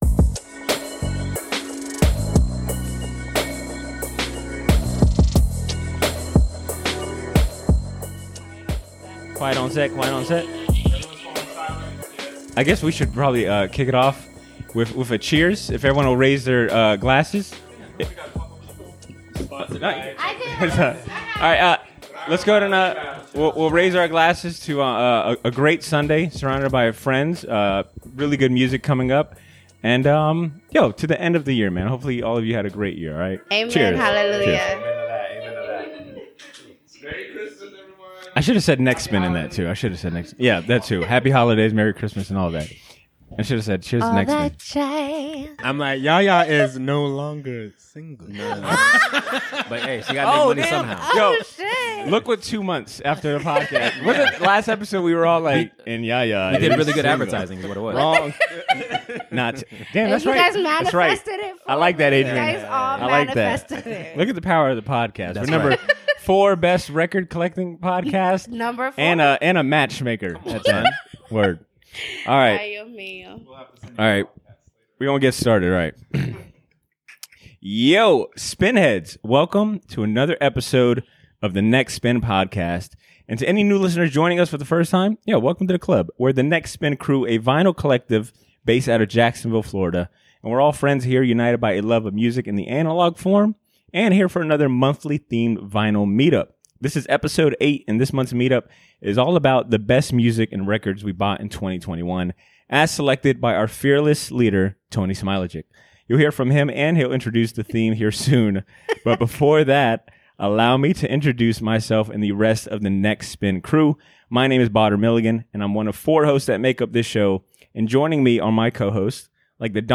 The crew celebrate one year of the podcast surrounded by friends, and returning guest co-host